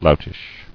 [lout·ish]